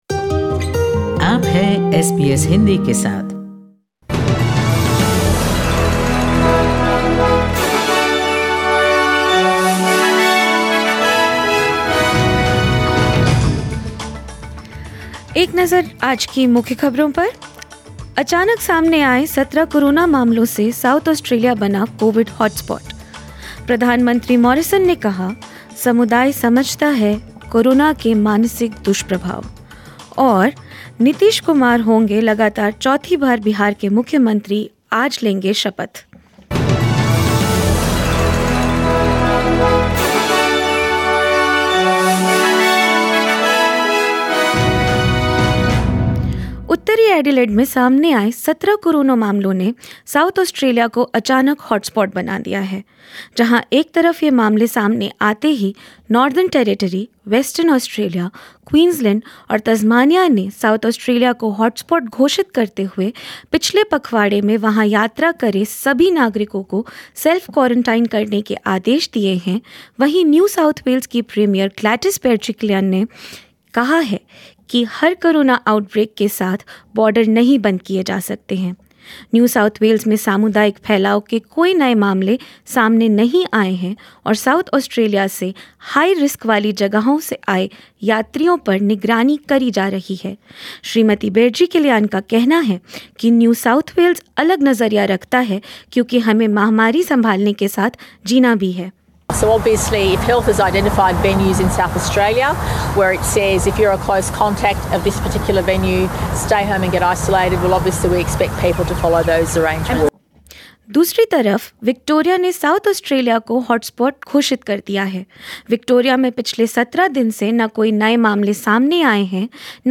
News in Hindi 16 November 2020